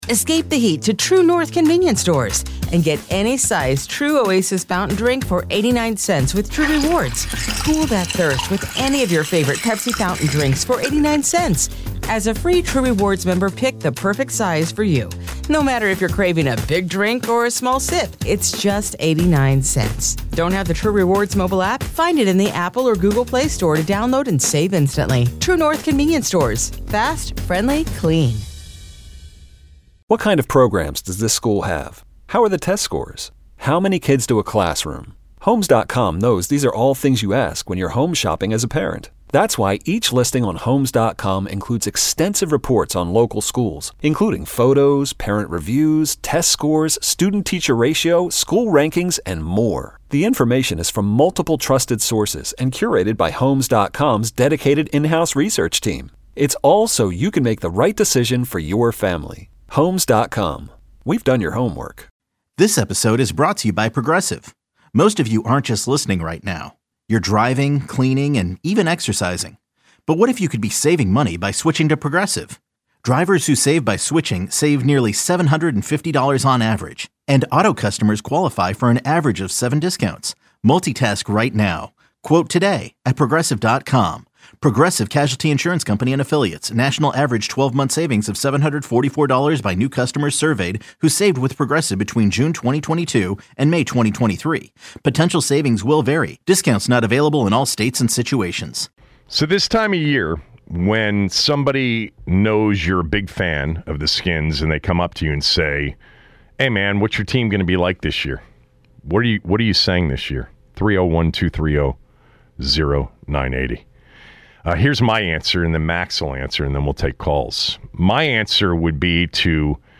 callers give their prediction